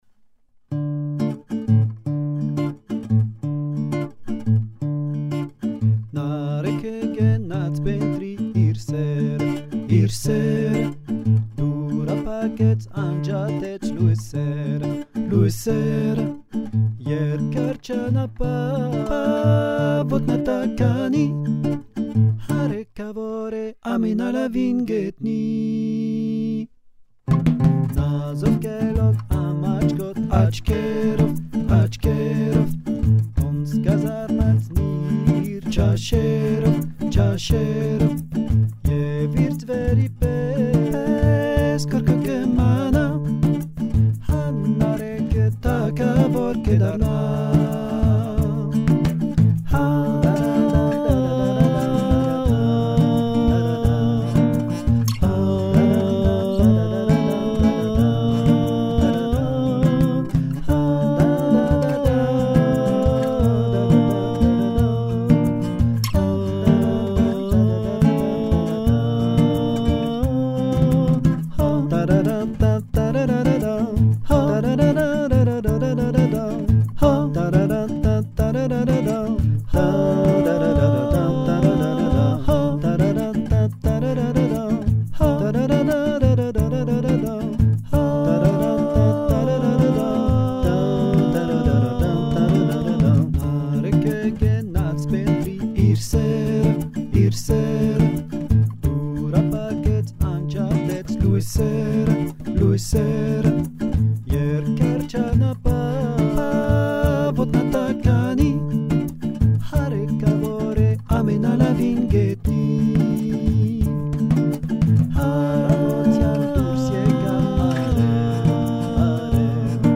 Voix 2 :